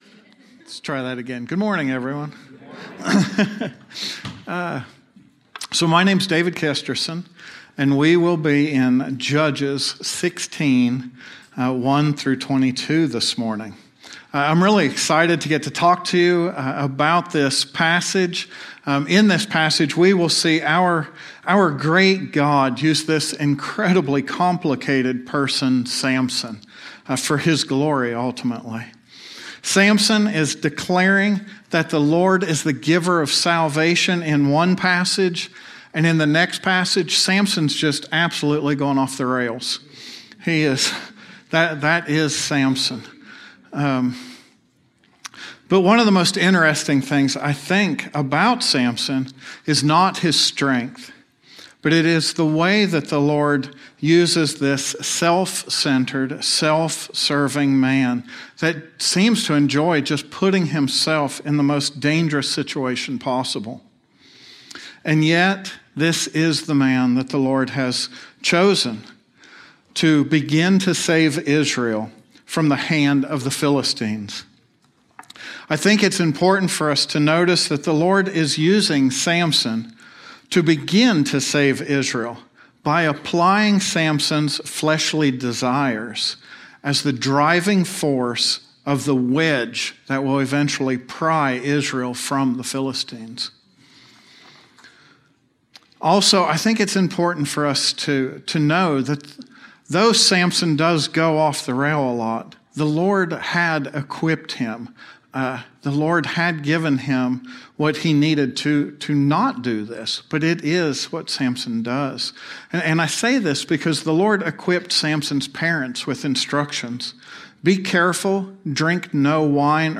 A sermon on Judges 16:1-22